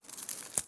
SFX_Harvesting_01_Reverb.wav